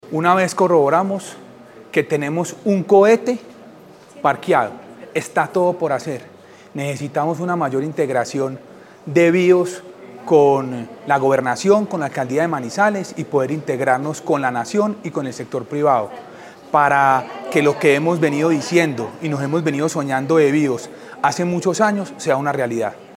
Audio Camilo Gaviria, diputado de Caldas